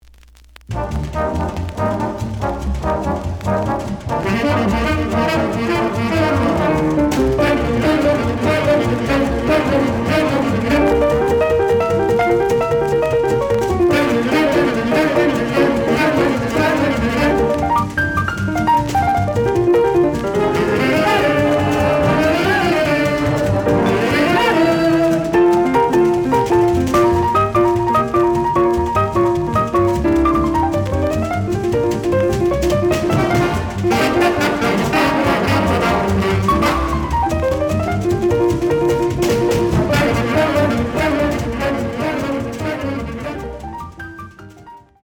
The audio sample is recorded from the actual item.
●Format: 7 inch
●Genre: Jazz Other